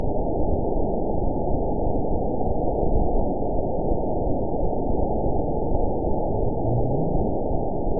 event 921841 date 12/19/24 time 22:19:50 GMT (11 months, 2 weeks ago) score 8.97 location TSS-AB02 detected by nrw target species NRW annotations +NRW Spectrogram: Frequency (kHz) vs. Time (s) audio not available .wav